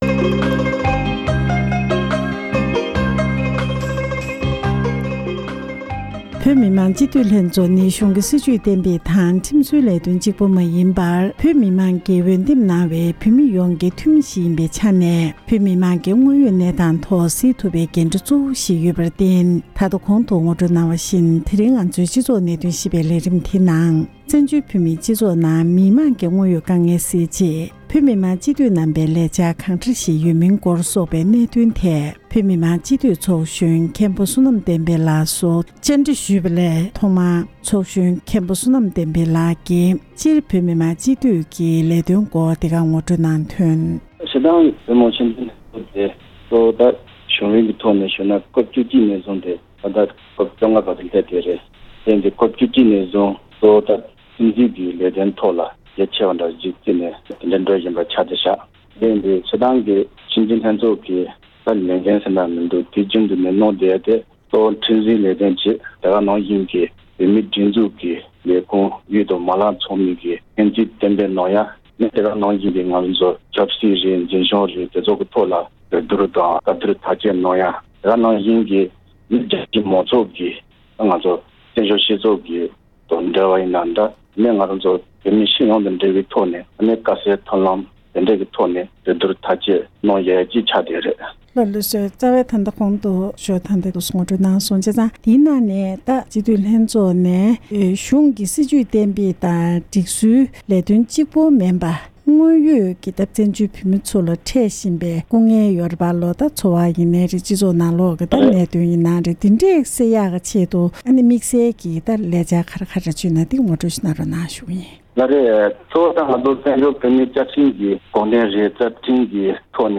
གནས་འདྲི་ཞུས་པ་ཞིག